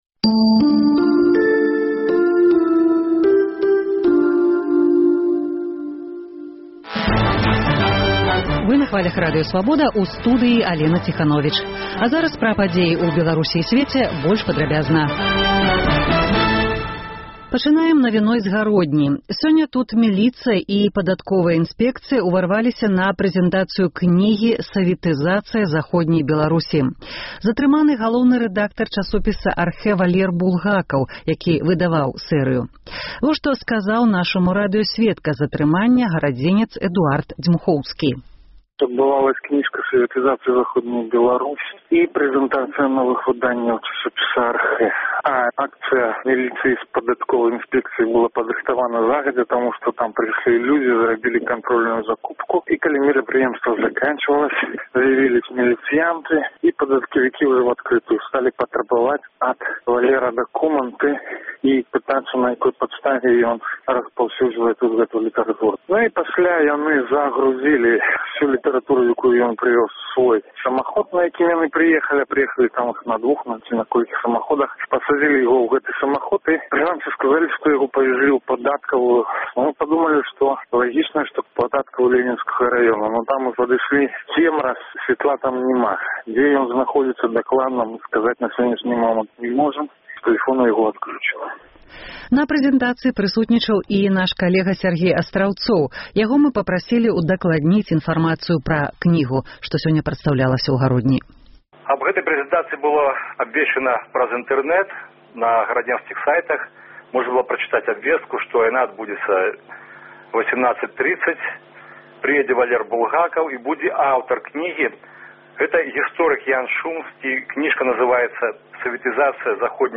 Падсумаваньне дня, бліц-аналіз, галасы людзей.